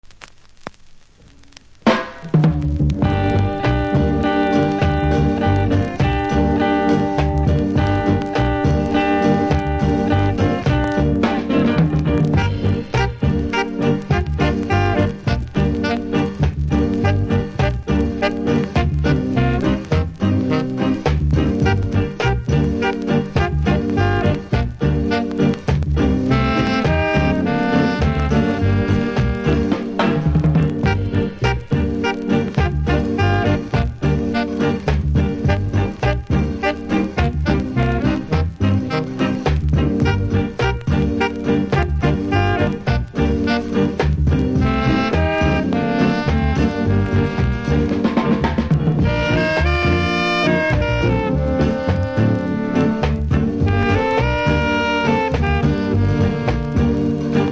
キズ多めでノイズもそこそこありますので試聴で確認下さい。